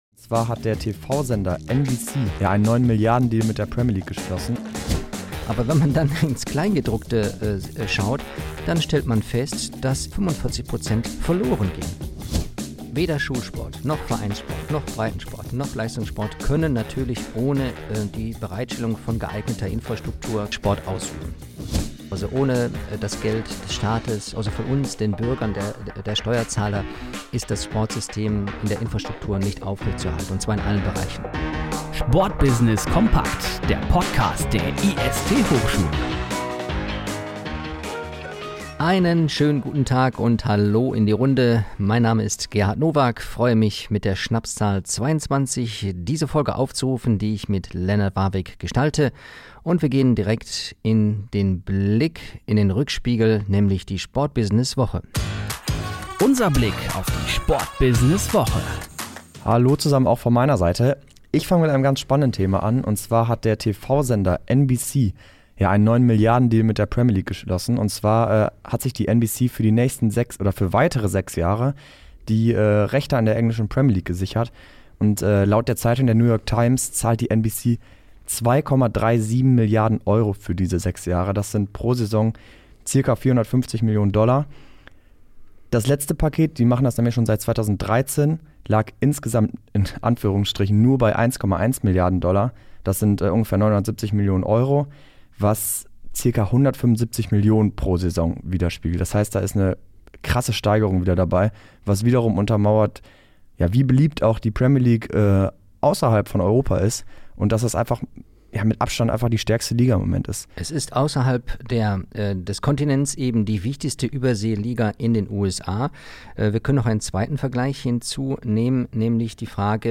diskutieren leidenschaftlich über Fußball, Basketball, F1, Handball und Co.